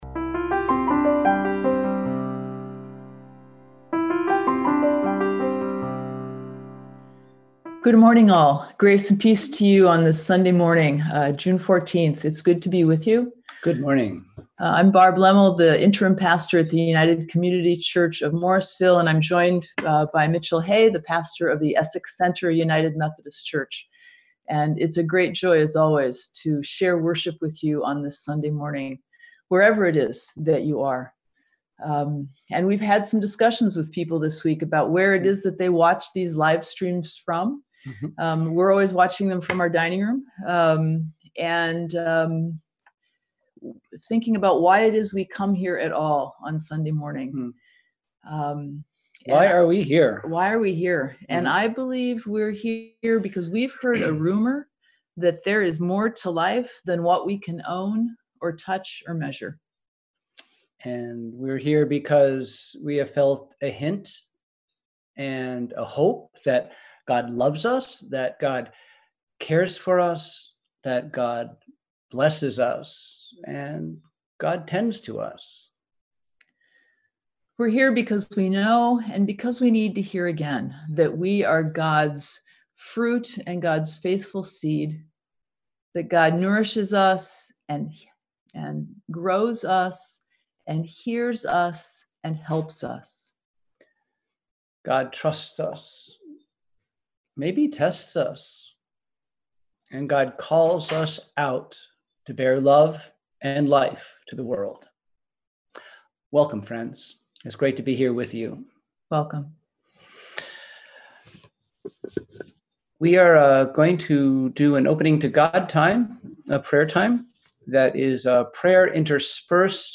We held virtual worship this week!